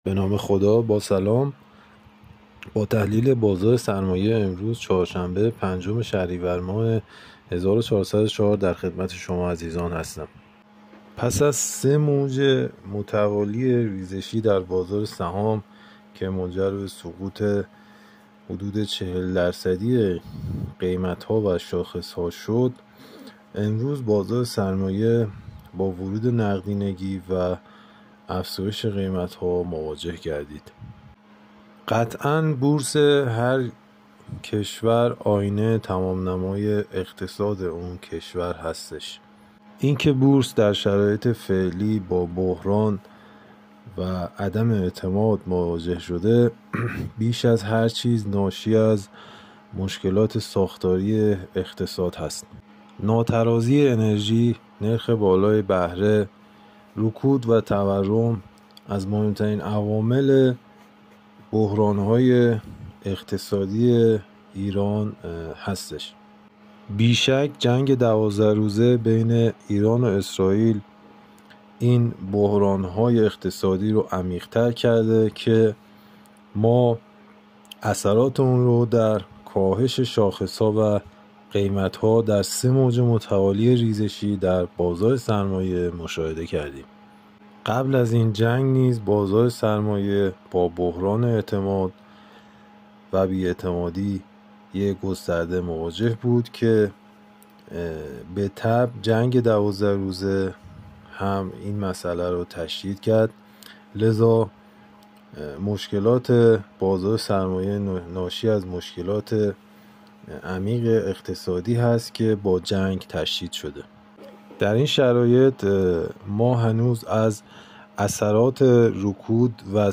کارشناس بازار سرمایه در گفت‌وگو با بورس‌نیوز